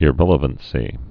(ĭ-rĕlə-vən-sē)